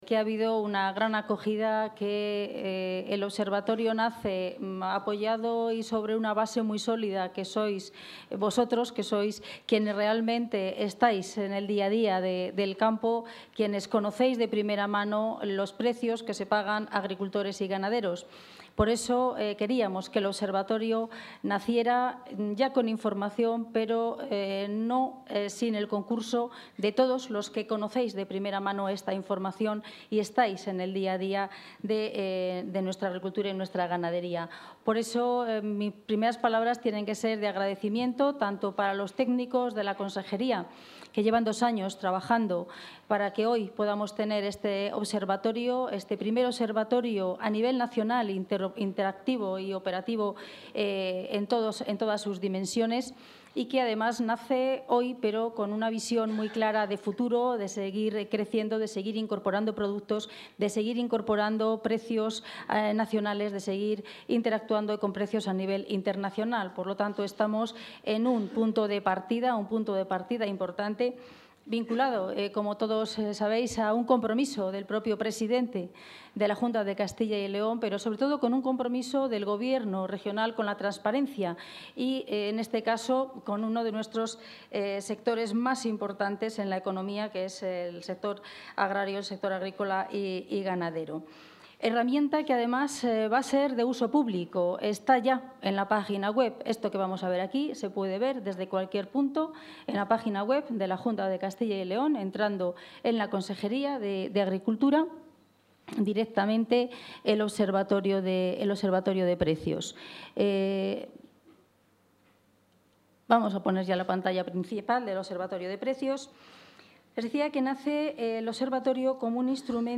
Intervención de la consejera de Agricultura y Ganadería.